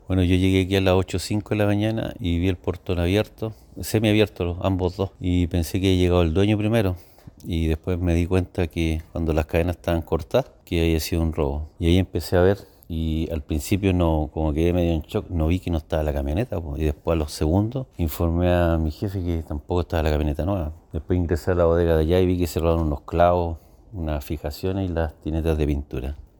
Así lo relató lo sucedido el mismo trabajador.